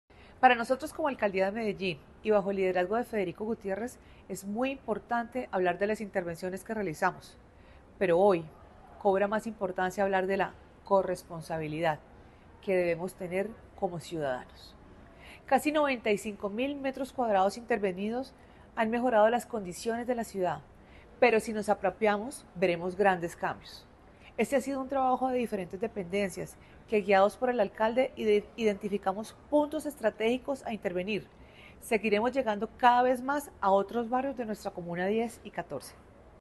Palabras de Juliana Coral, gerente del Centro y Territorios Estratégicos